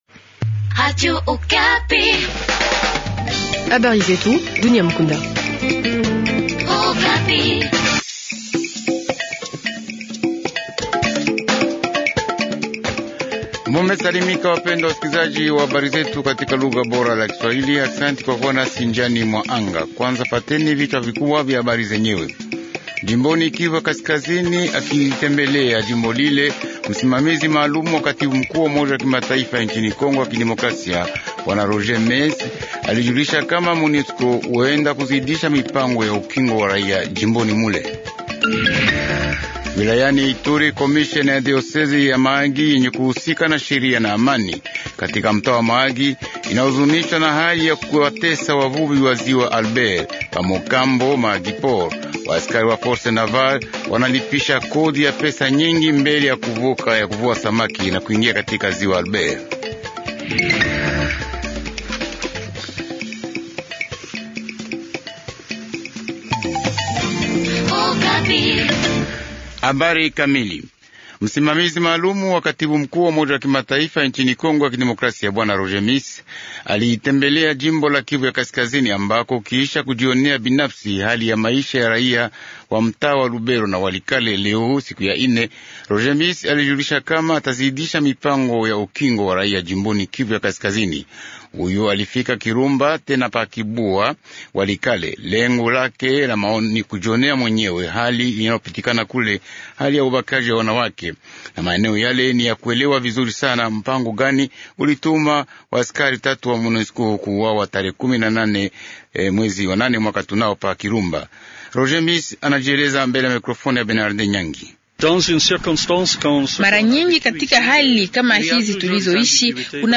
Journal Swahili Soir